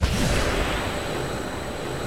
CharaMario_Arwing_move.wav